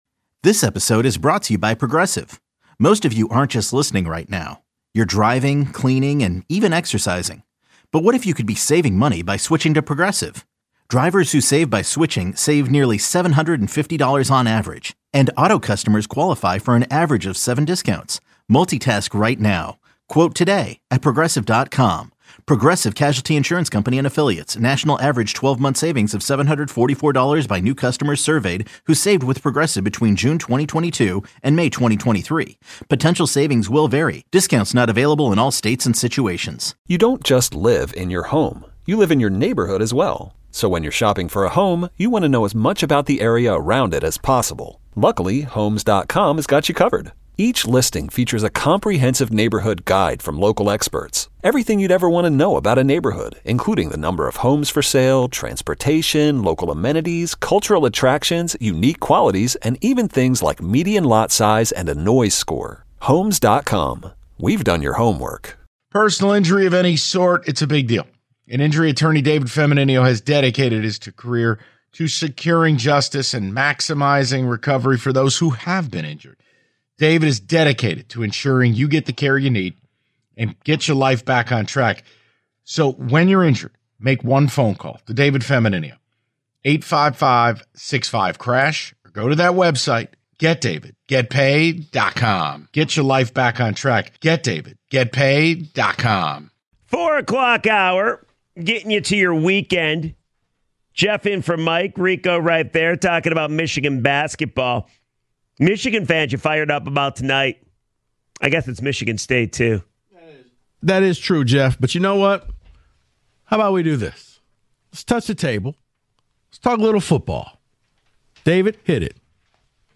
The guys kick off hour 3 with part 2 of the "In Football Today" from earlier. Then, they take some of your calls on Michigan/MSU before doing "Who Said It?" to finish the hour.